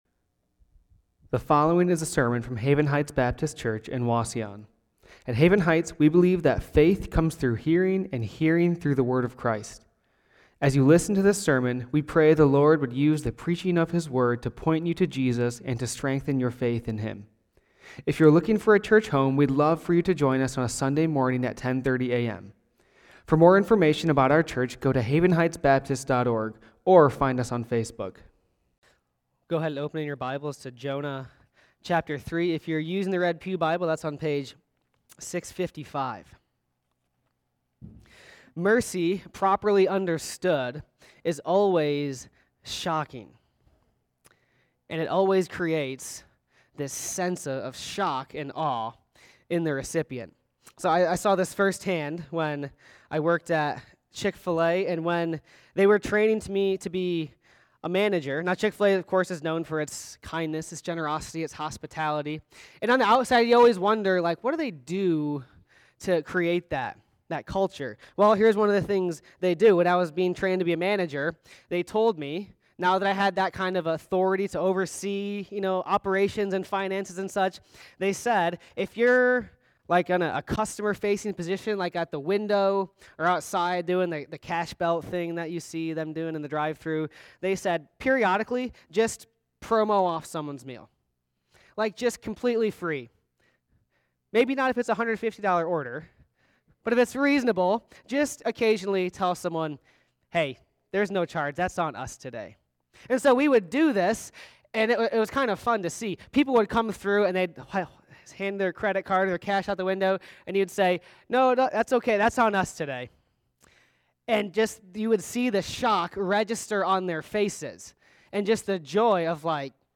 Sermons | Haven Heights Baptist Church